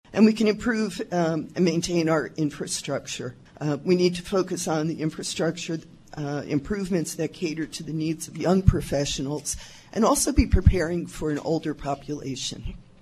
During this past weekend’s League of Women Voters Forum on KMAN, candidates were asked what the city’s role should be in spurring development of jobs that provide a livable wage.
Seven took part in the forum Saturday at the Manhattan Public Library, hosted by the League and co-sponsored by the local chapter of the American Association of University Women and the Manhattan Area Chamber of Commerce.